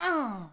SFX_Mavka_Hit_Voice_09.wav